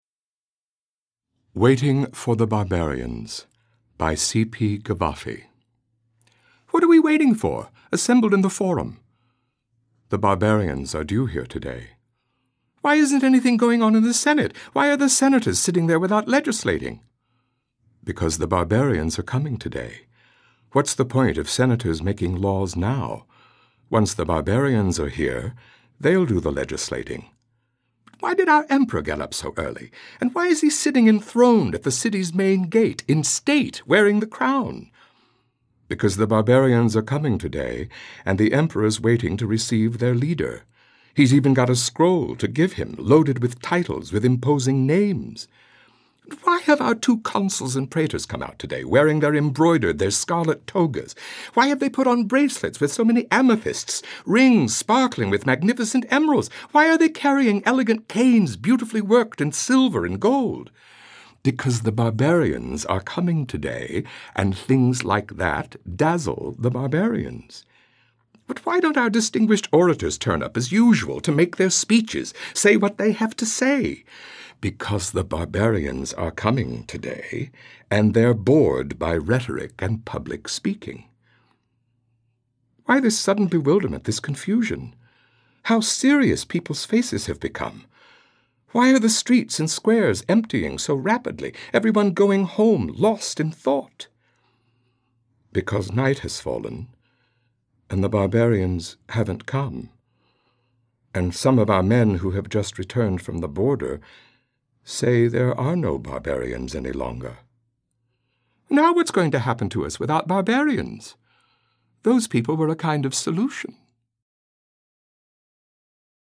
Daniel Davis reads "Waiting for the Barbarians" by C. P. Cavafy
In celebration of National Poetry Month, every day we're posting a new poem from the spoken-word album Poetic License, a three-CD set that features one hundred performers of stage and screen reading one hundred poems selected by the actors themselves.
Daniel Davis, who portrayed Niles the butler in the television series The Nanny, has appeared on Broadway stages in La Cage aux Folles, The Invention of Love, Wrong Mountain, and Othello, among other plays.